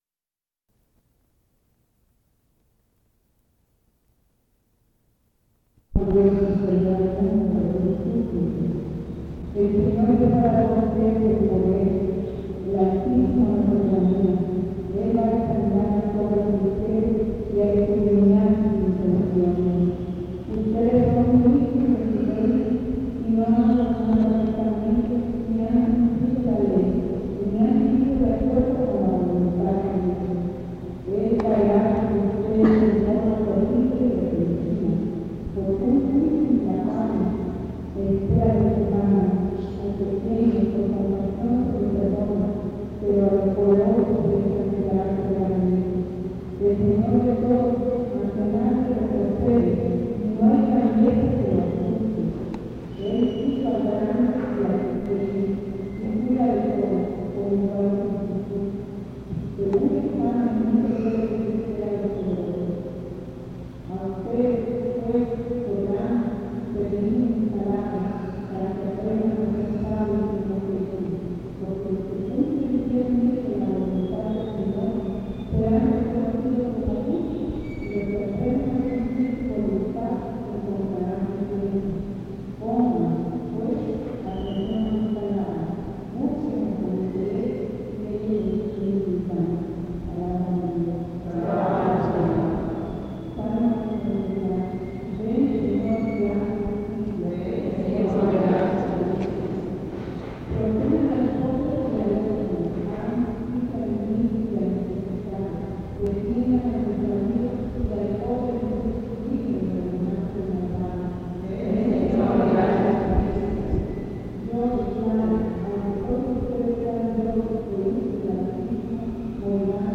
02 Misa en la Parroquia de San Cristóbal
Paisaje sonoro